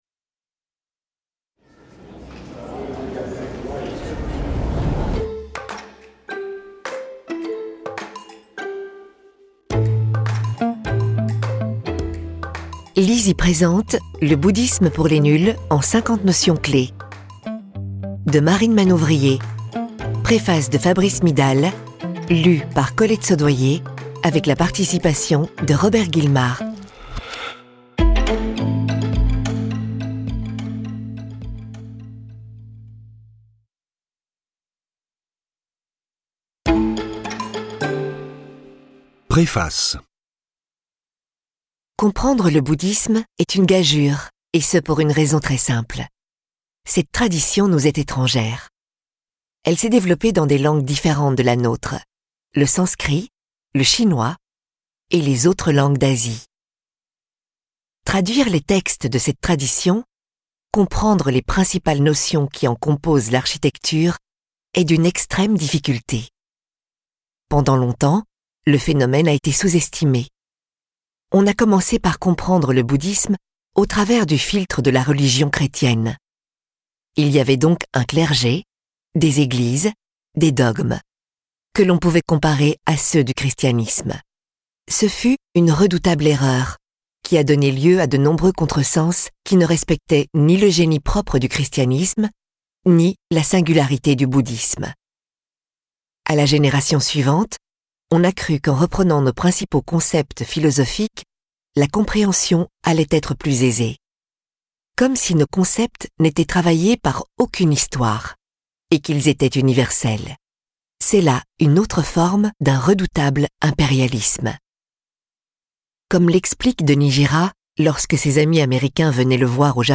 Malgré ces chiffres éloquents, nous connaissons peu ou mal le bouddhisme. Ce livre audio permettra à tous ceux que le bouddhisme intéresse, de découvrir les 50 principes essentiels qui le composent : bouddha, le dharma, le samsara, les quatre nobles vérités, et bien d'autres choses encore, n'auront plus de secret pour l'auditeur qui pourra enfin comprendre toute la dimension philosophique, spirituelle et humaine du bouddhisme.